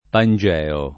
[ pan J$ o ]